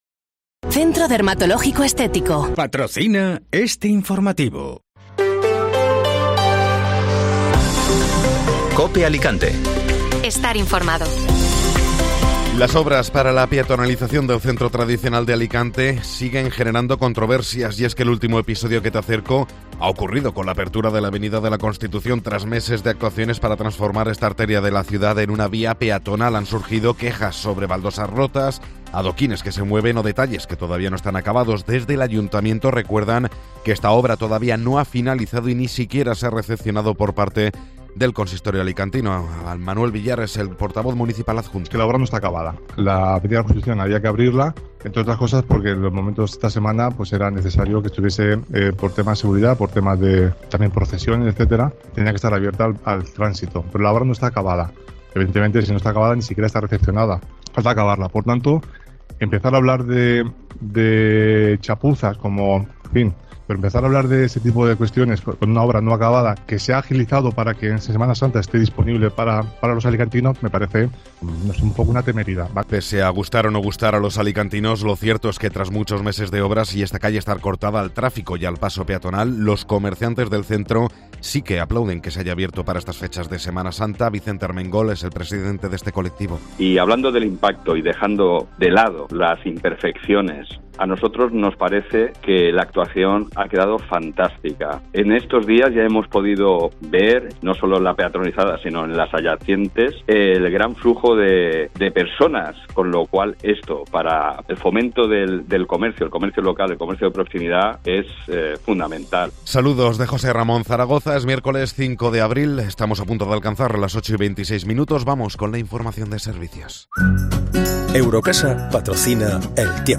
Informativo Matinal (Miércoles 5 de Abril)